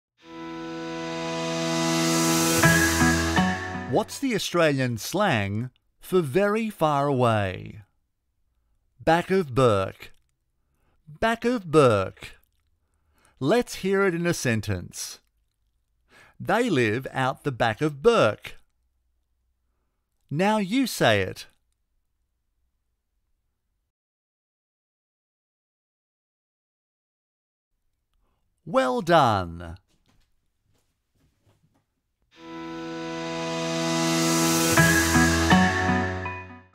Male
I can also provide a mid-range energy with soft tones and variety in my read.
My accent is Australian English.
E-Learning
Words that describe my voice are Australian, Narrator, Voice over.